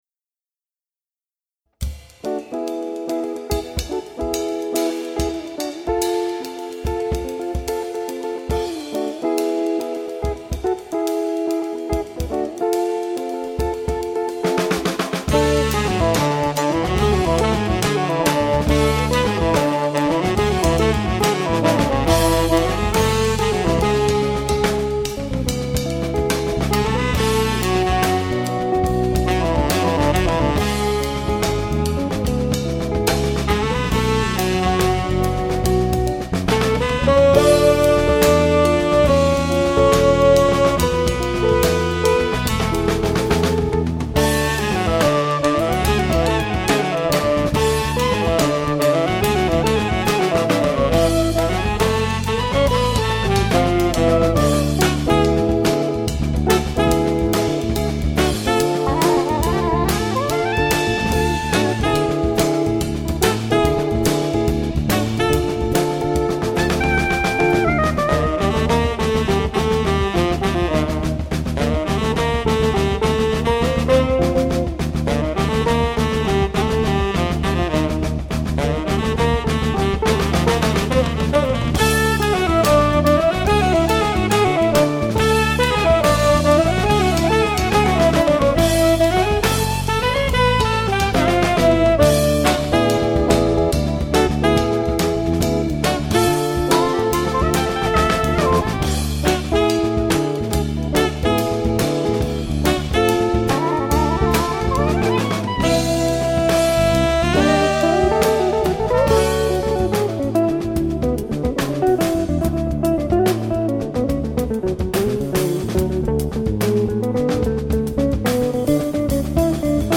chitarra
batteria
basso
sax